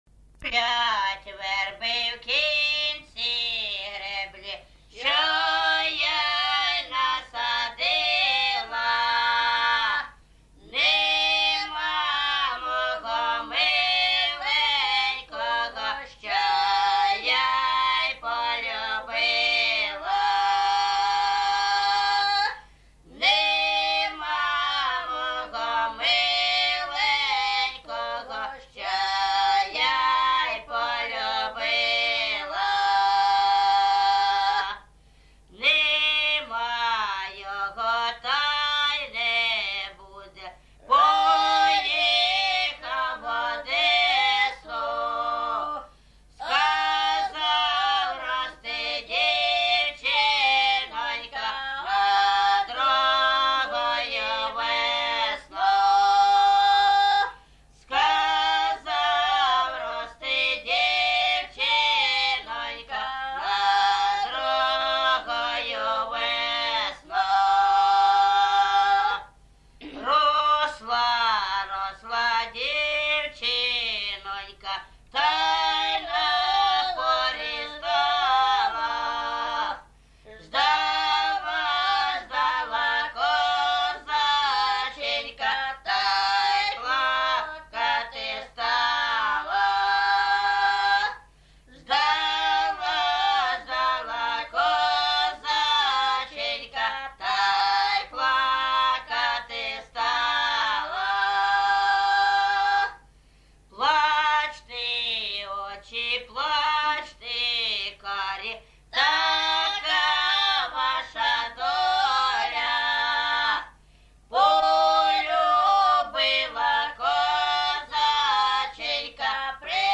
ЖанрПісні з особистого та родинного життя
Місце записус. Гарбузівка, Сумський район, Сумська обл., Україна, Слобожанщина